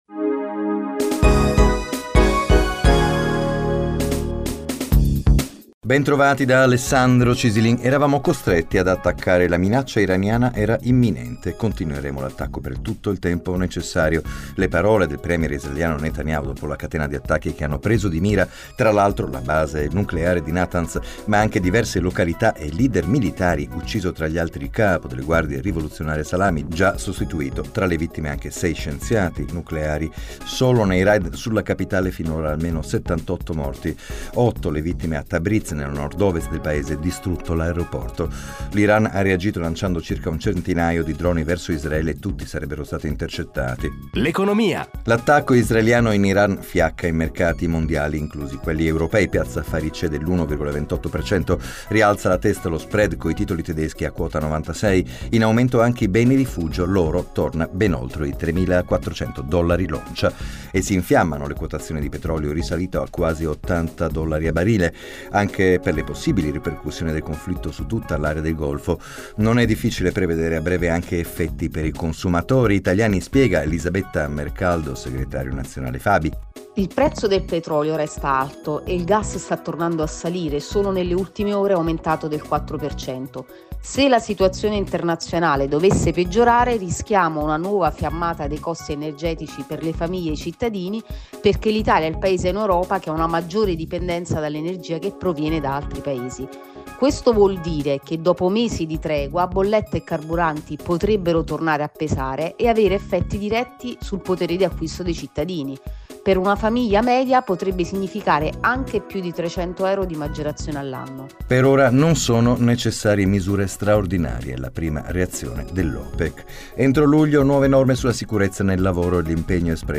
Intervista sul sovraindebitamento